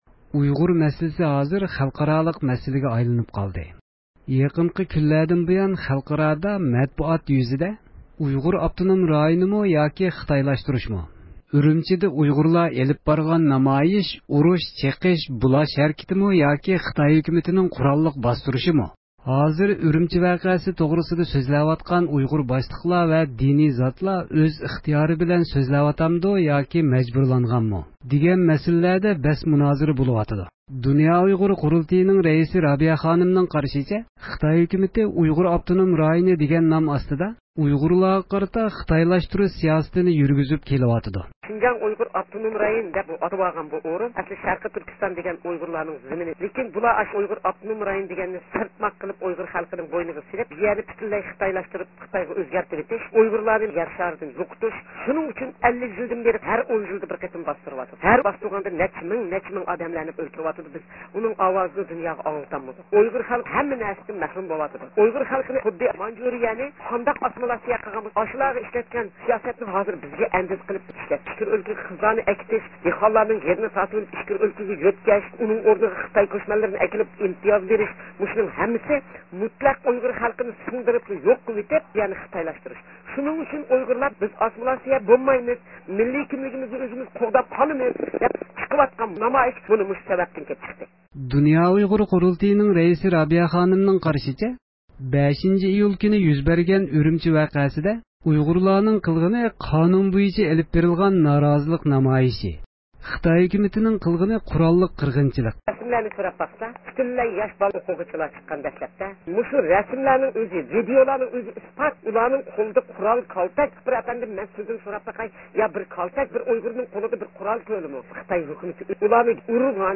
دۇنيا ئۇيغۇر قۇرۇلتېيىنىڭ رەئىسى ئۇيغۇر رابىيە خانىم بۈگۈن رزدىئومىزنىڭ زىيارىتىنى قوبۇل قىلىپ، خىتاي ھۆكۈمىتىنىڭ ئۈرۈمچى ۋەقەسىنى ›ئۇرۇش، چېقىش، بۇلاش‹ ھەركىتى دېگەنلىكى، خىتاي ھۆكۈمىتىنىڭ ›ئۇيغۇر ئاپتونوم رايونى‹ دېگەن سىياسىتى ۋە خىتاي ھۆكۈمىتى ئۆزى تاللىۋالغان ئۇيغۇر باشلىقلىرىنىڭ قىلىۋاتقان سۆزلىرى ھەققىدە توختالدى.